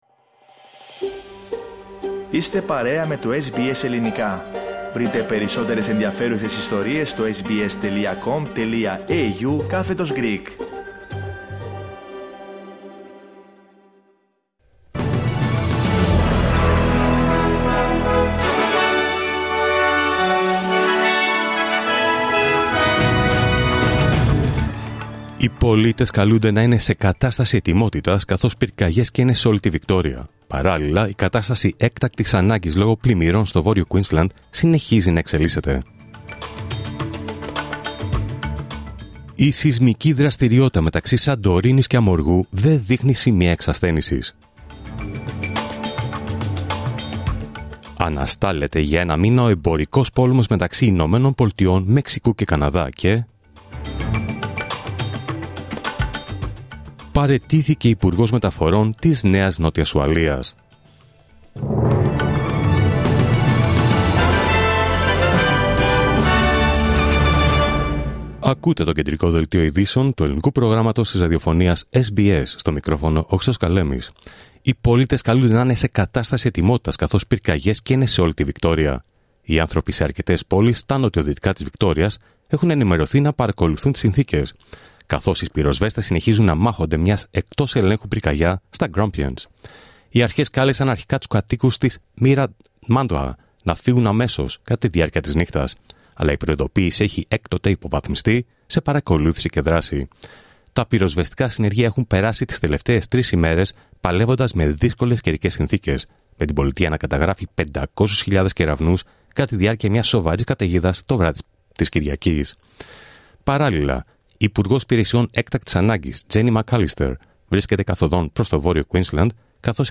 Δελτίο Ειδήσεων Τρίτη 4 Φεβρουάριου 2025